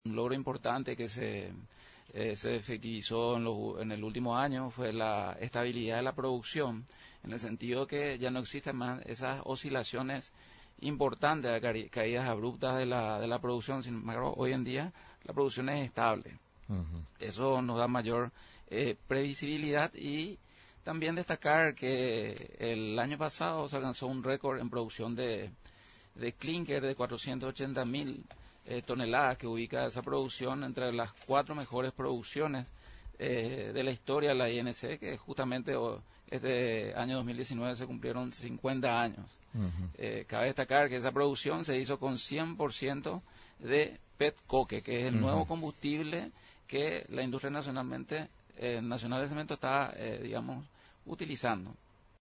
durante la visita a los estudios de Radio Nacional del Paraguay (RNP).